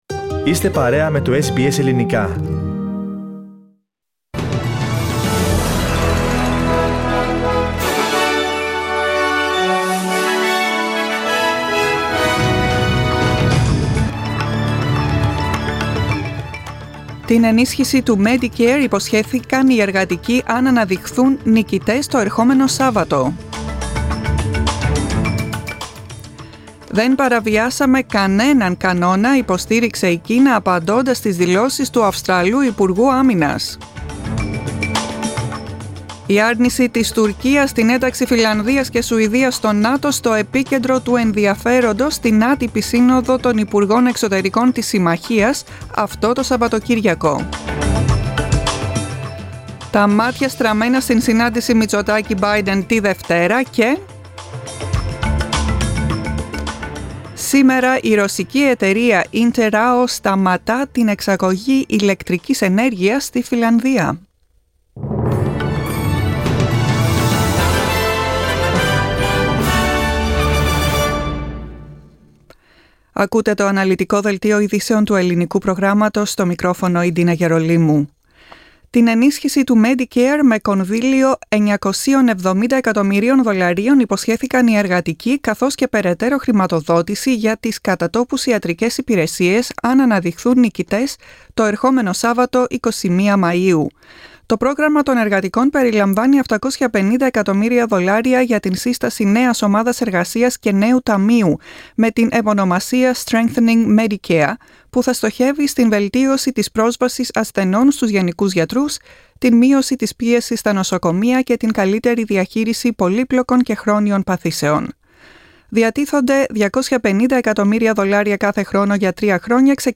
Δελτίο ειδήσεων, 14.05.22